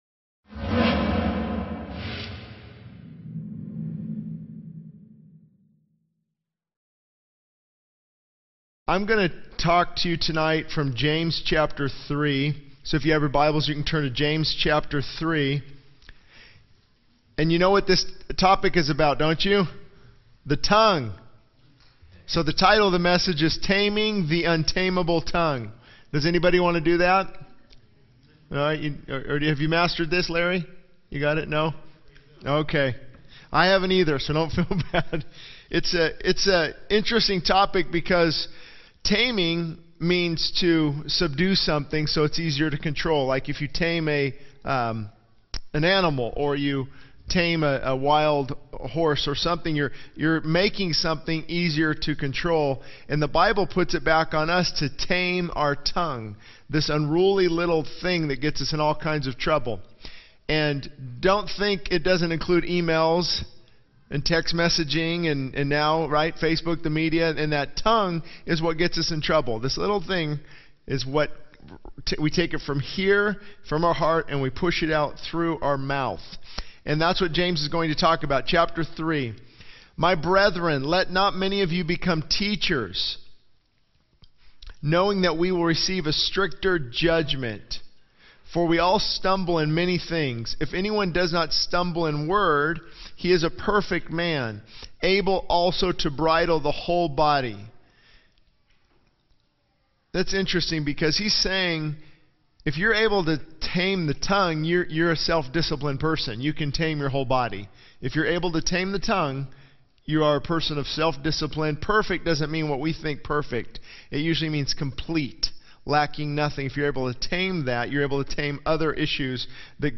This sermon focuses on the importance of taming the tongue, highlighting the power of words to bring life or destruction, the need for self-discipline and maturity in controlling speech, and the wisdom from above that leads to peace, gentleness, and willingness to yield. The message emphasizes the impact of the tongue on relationships, leadership, and spiritual maturity, urging listeners to align their words with God's truth and seek purity in speech.